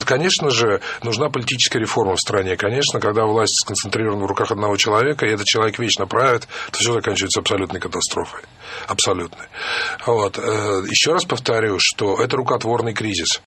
Вот что сказал Борис Немцов в интервью "Эху Москву" за несколько часов до убийства